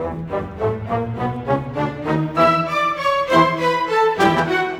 Rock-Pop 20 Orchestra 01.wav